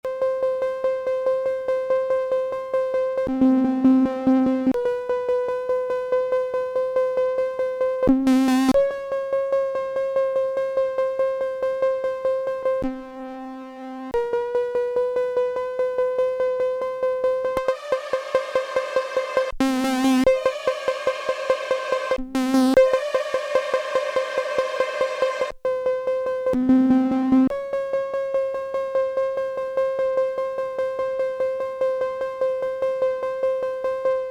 When changing patches the intonation is sometimes off for about half a second and then stabilises.
I recorded a little audio take where I keep repeatedly playing C while switching patches. You can clearly hear the instability of the intonation I was referring to (I think).